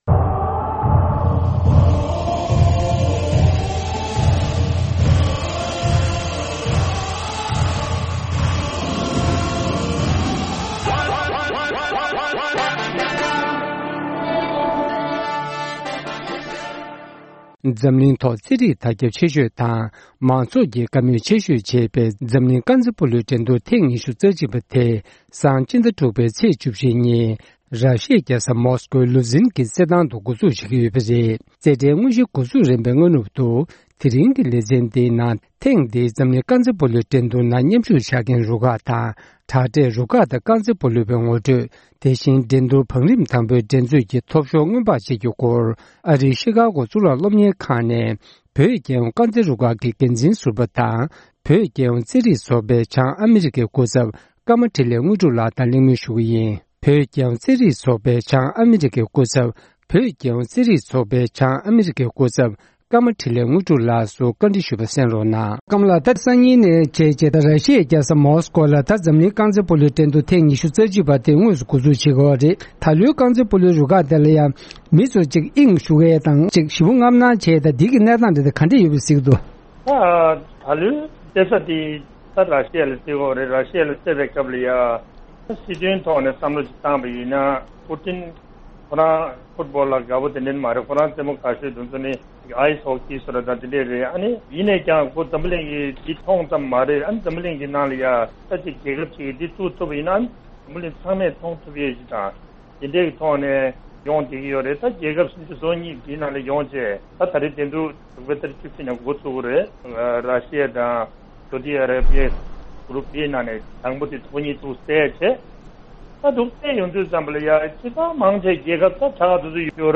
བཅར་འདྲི